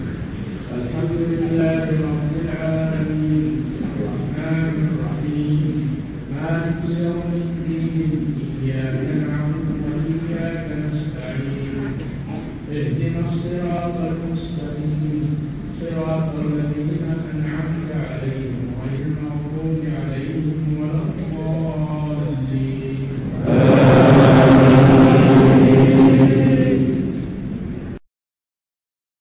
قراءات نادرة للشيخ بقية السلف ( العلامة صالح الفوزان) حفظه الله
الفاتحة2 قراءة نادرة